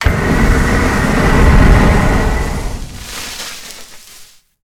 flamethrower_shot_06.wav